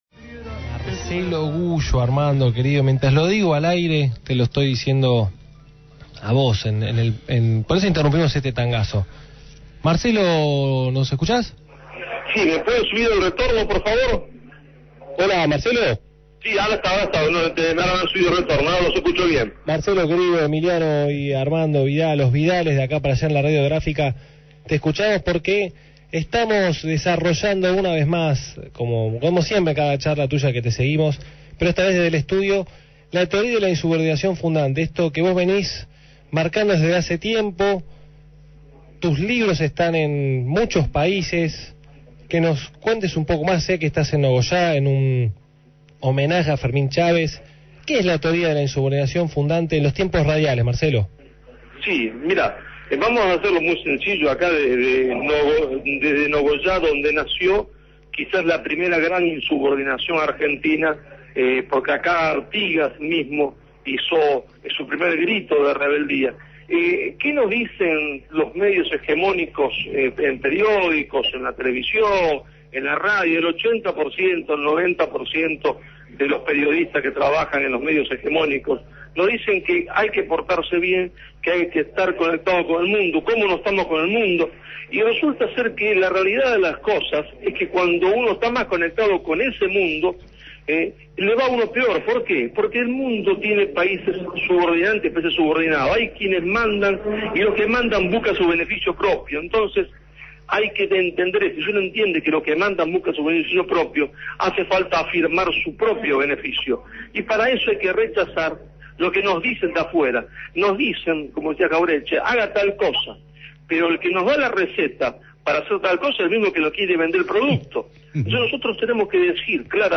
El politólogo y miembro del Instituto Nacional de Revisionismo Histórico Argentino e Iberoamericano Manuel Dorrego, Marcelo Gullo estuvo el sábado en el programa De acá para Allá explicando su teoría de la «Insubordinación Fundante”, la cual resume los procesos de desarrollo e industrialización de las naciones que llegaron a convertirse en países industriales.